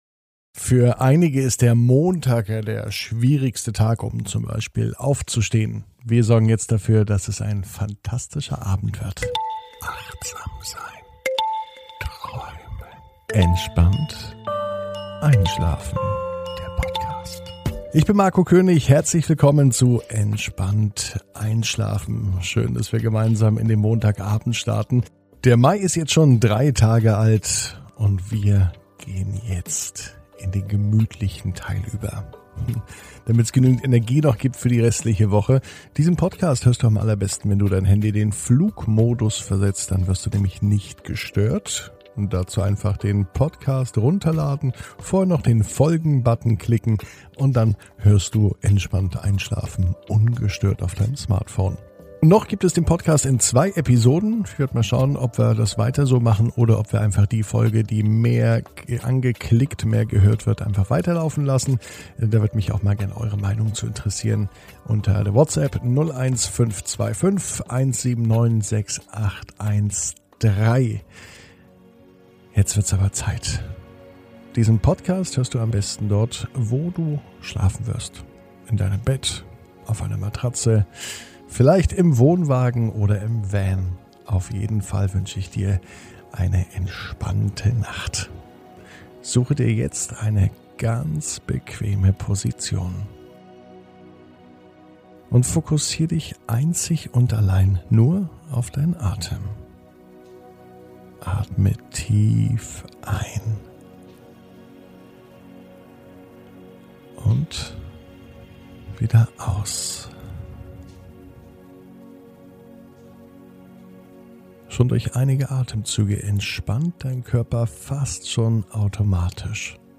Entspannt einschlafen am Montag, 03.05.21 ~ Entspannt einschlafen - Meditation & Achtsamkeit für die Nacht Podcast